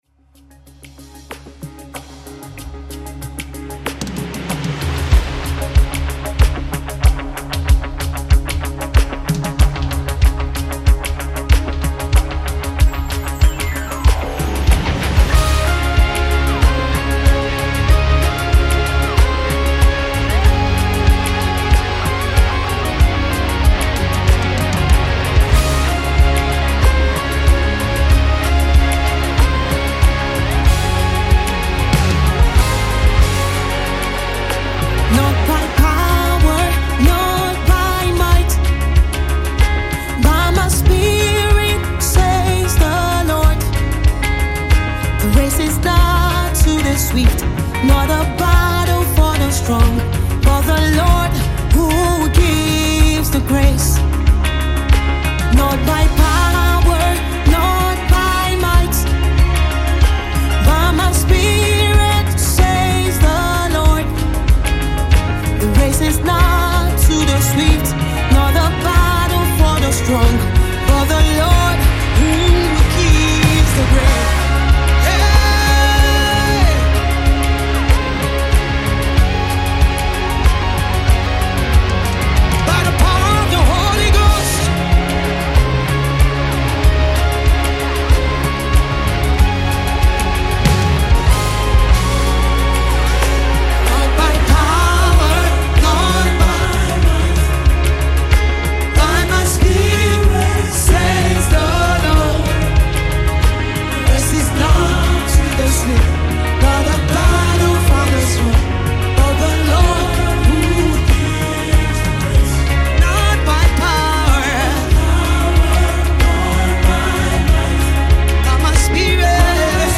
faith-boosting anthem